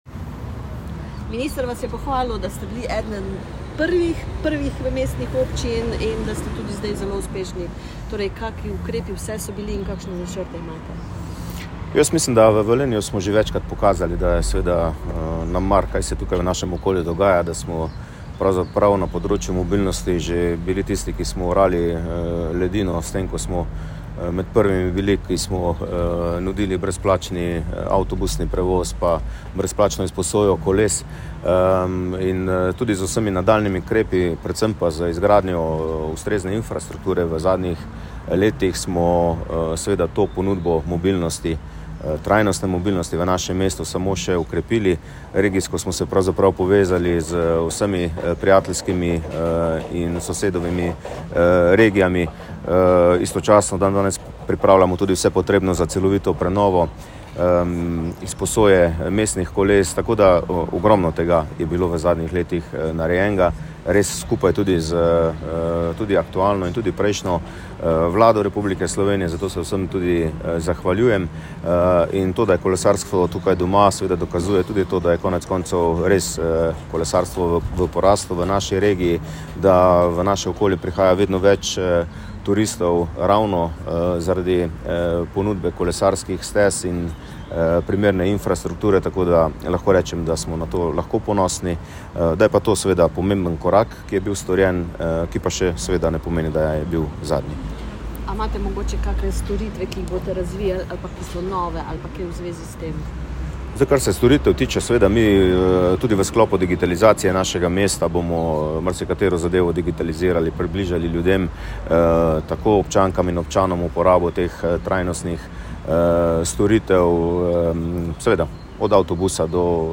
Tonska izjava župana Petra Dermola